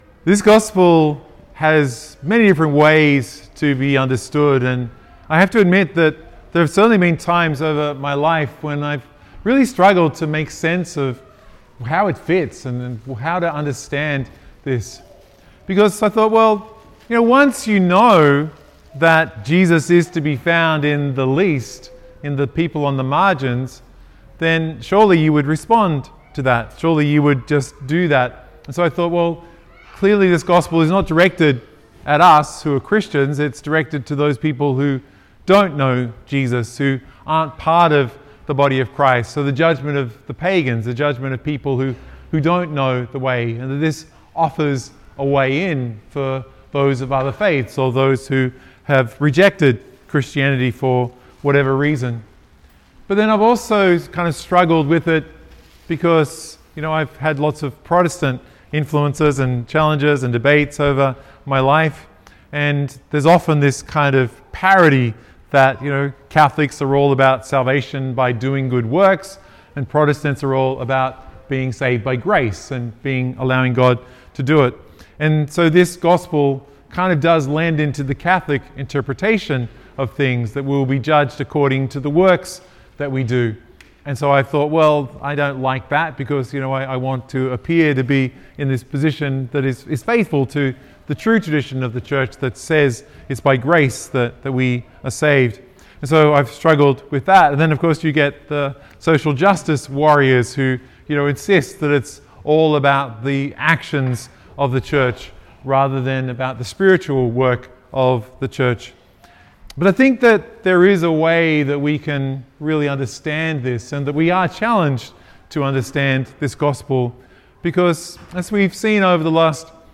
Liturgy of the Word